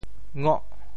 潮州拼音“ngoh8”的详细信息
潮州府城POJ ngôh 国际音标 [ŋo?]
ngoh8.mp3